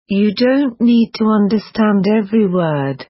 这个资源是一个英文的句子：You don't need to understand every word。
当播放最后一秒的时候，word中 d 没有发音完，就播放结束了。